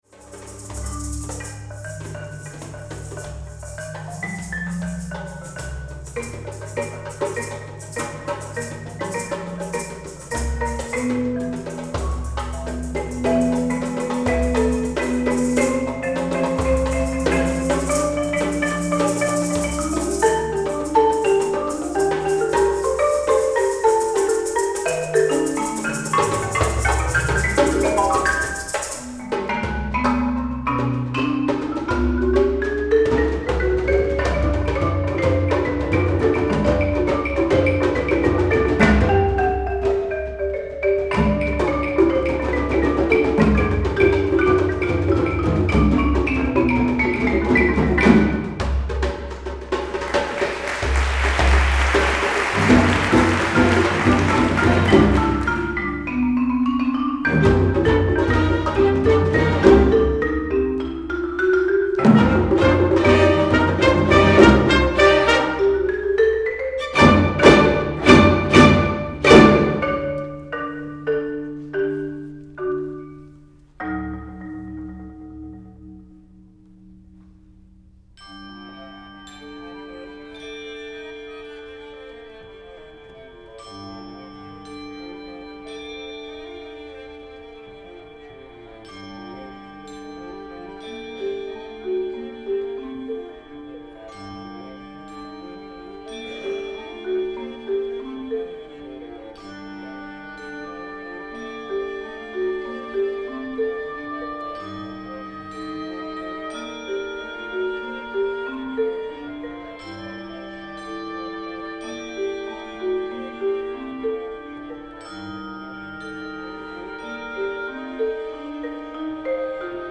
Category: Concerti, Masterworks
Ensemble: Full Orchestra, Chamber Orchestra
Instrument / Voice: Marimba